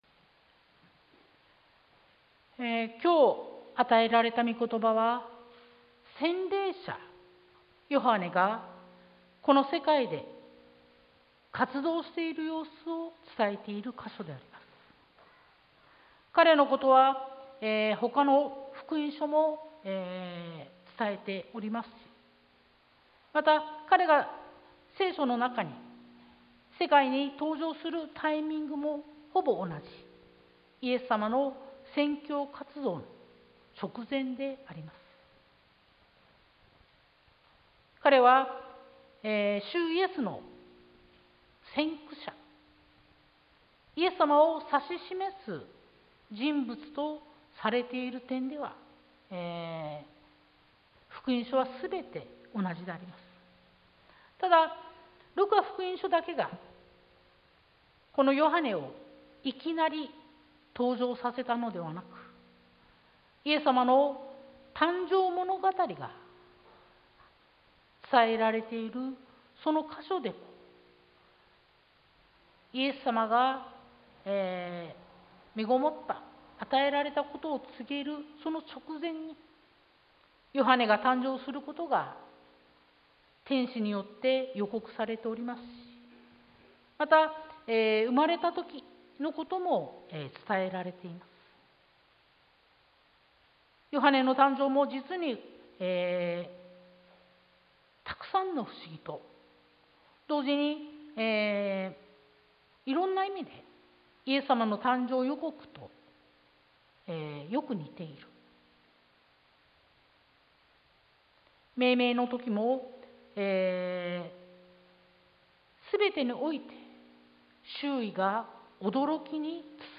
sermon-2022-05-08